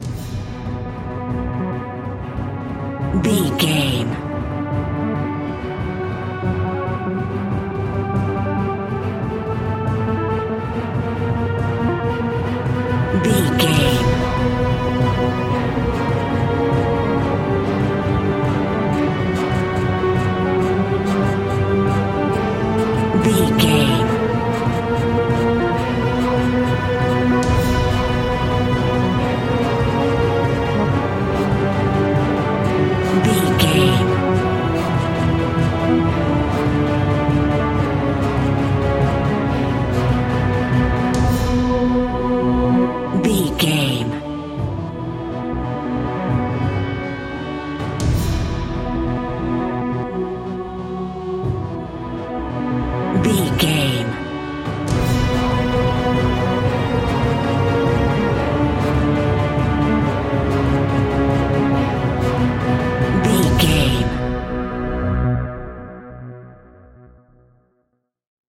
In-crescendo
Thriller
Aeolian/Minor
tension
ominous
dark
eerie
strings
synthesiser
drums
percussion
brass
pads